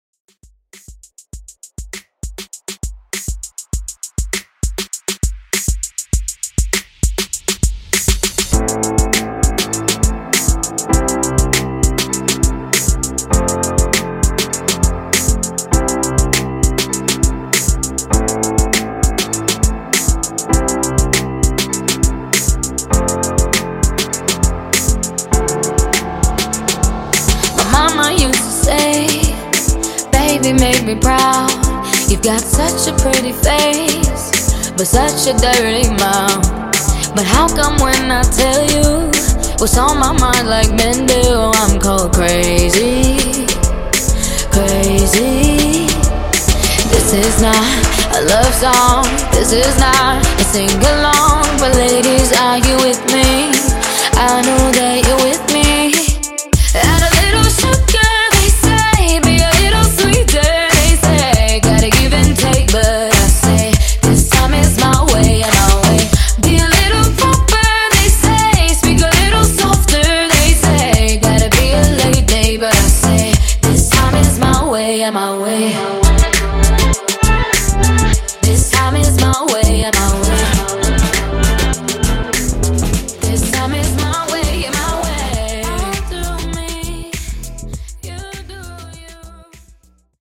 Top40 ReDrum)Date Added